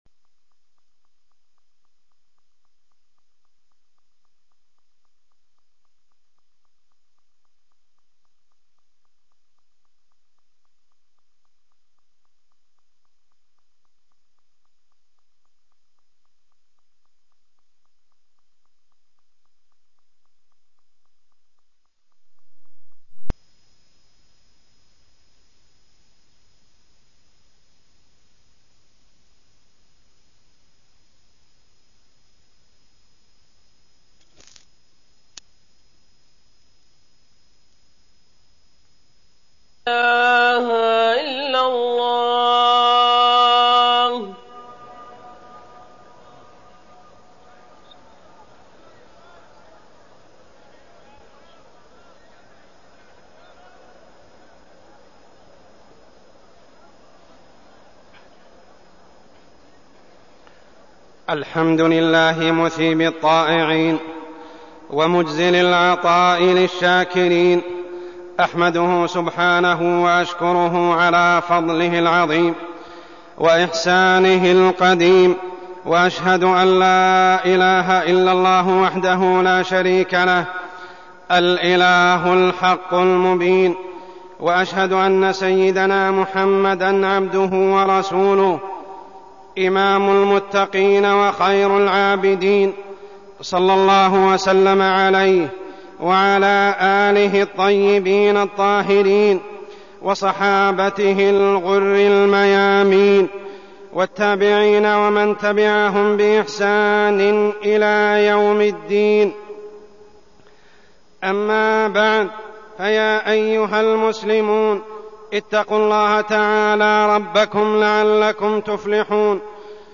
تاريخ النشر ٢٢ رمضان ١٤١٧ هـ المكان: المسجد الحرام الشيخ: عمر السبيل عمر السبيل شهر الإنتصارات والفتوحات